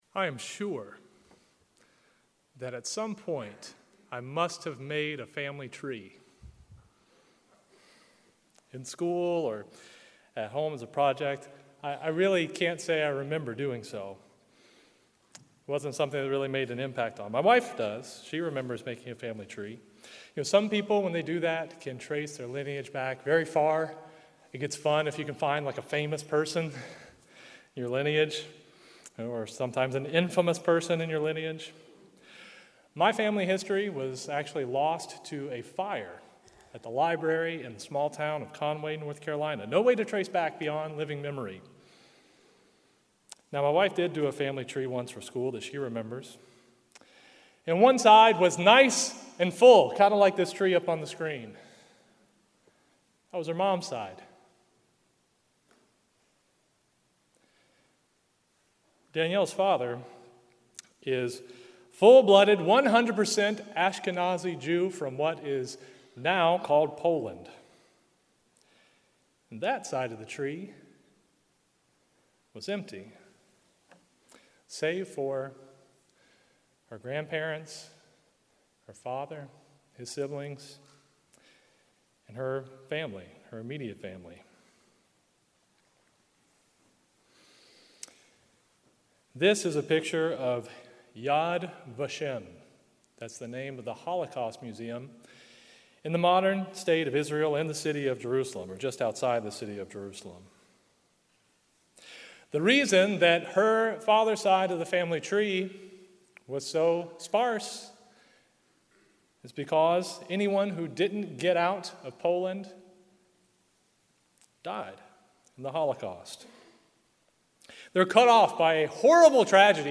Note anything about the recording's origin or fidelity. This sermon was given at the Ocean City, Maryland 2022 Feast site.